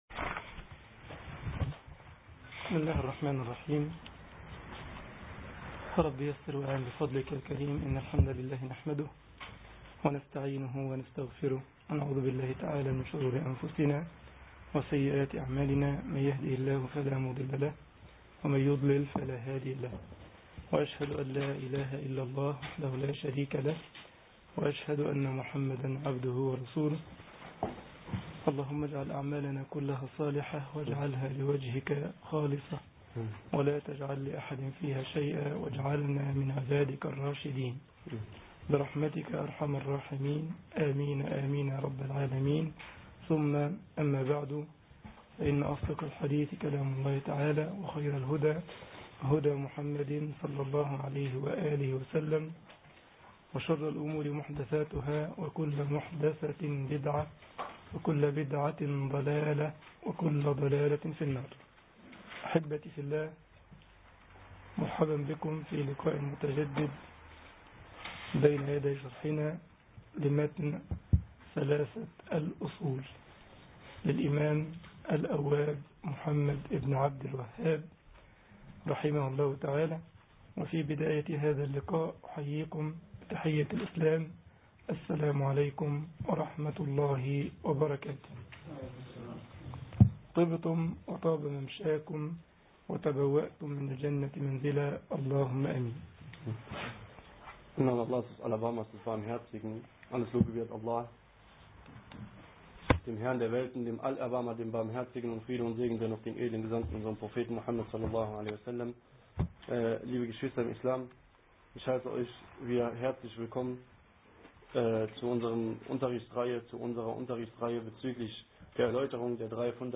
محاضرة
جمعية الشباب المسلمين بسلزبخ ـ ألمانيا